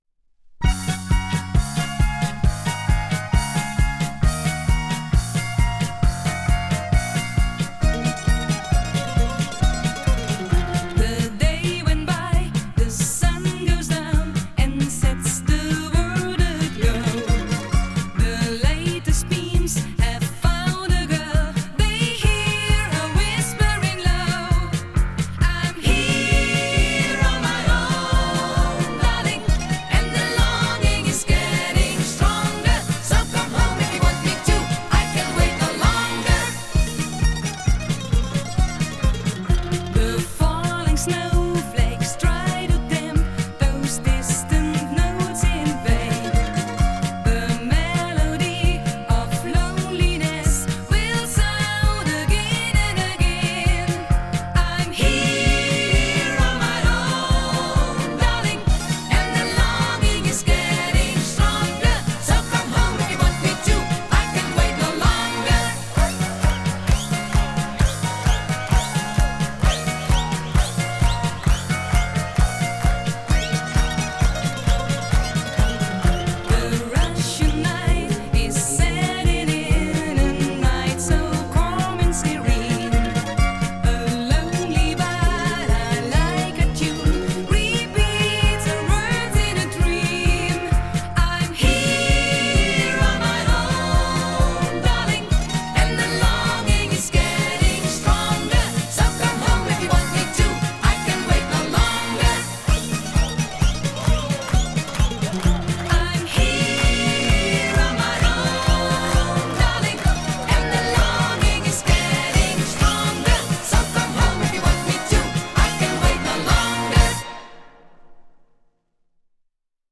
Жанр: Electronic, Rock, Funk / Soul, Pop
Recorded At – Dureco Studio